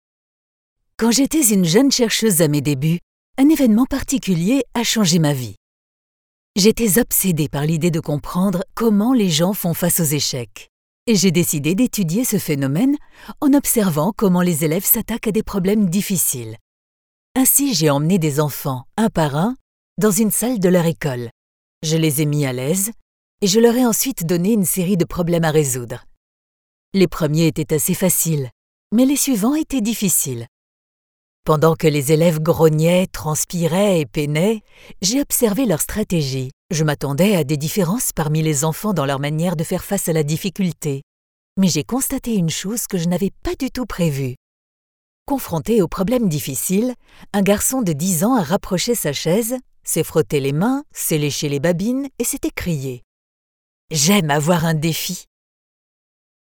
Französische Sprecherin. Flexible Stimme.
Sprechprobe: Sonstiges (Muttersprache):
French native Voice artist with professional Home Studio.